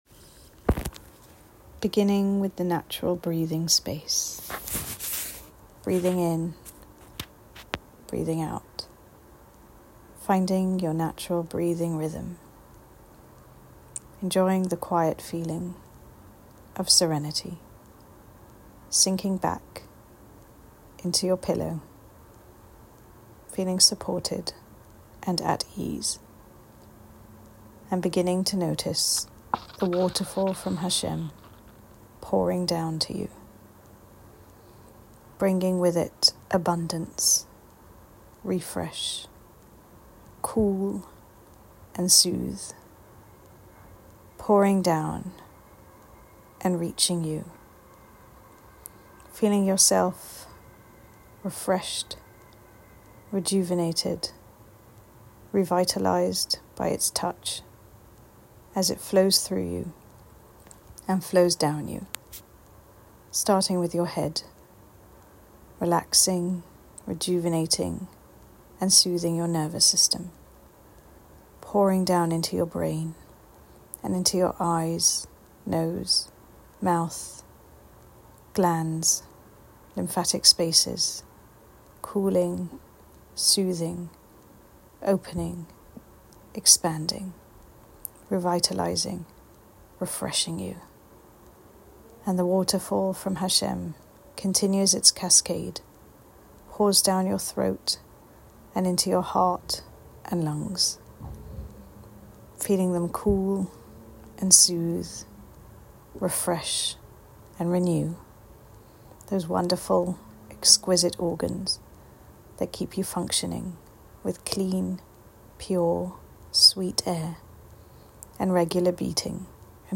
Enjoy and feel free to share TorahPsych’s collection of breathing and visualisation exercises to help you calmly tolerate feelings.